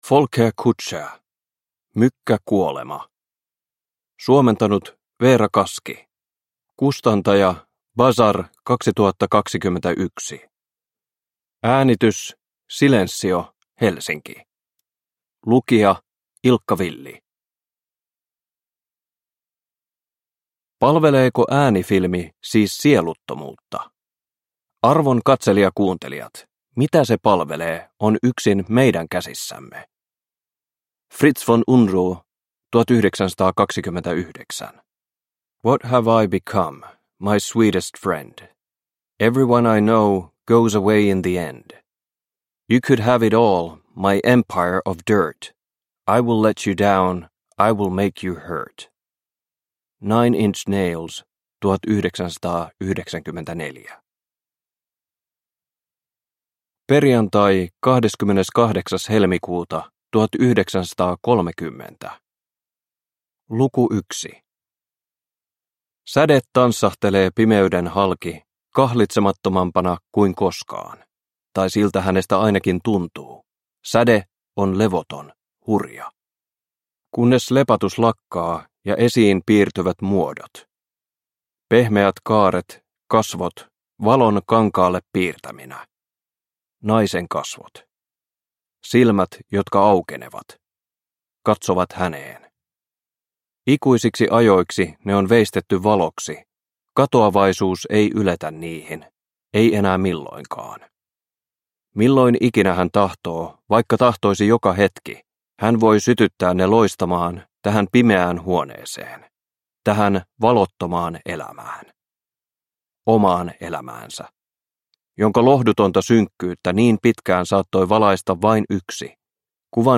Mykkä kuolema – Ljudbok – Laddas ner